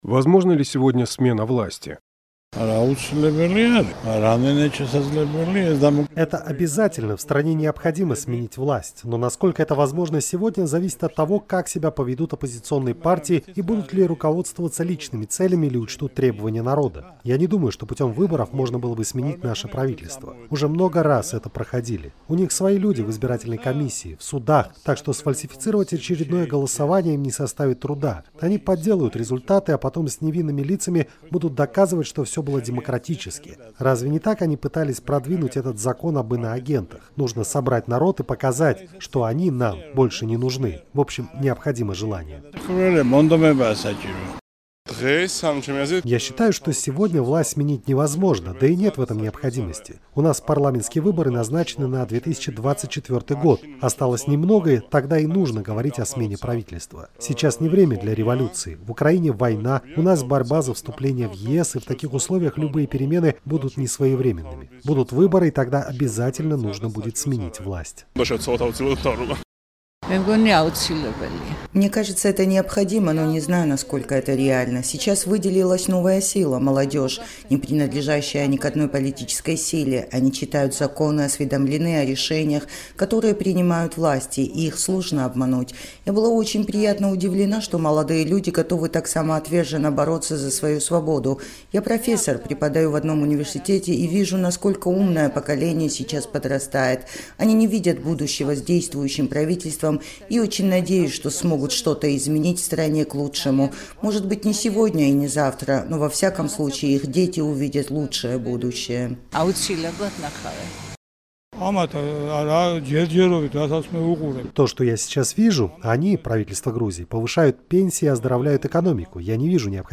Готово ли государство к этому и как вообще может смениться власть в стране? «Эхо Кавказа» обратилось с этим вопросом к жителям Тбилиси.